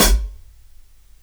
Index of /4 DRUM N BASS:JUNGLE BEATS/KIT SAMPLES/DRUM N BASS KIT 1
HIHAT EDGE.wav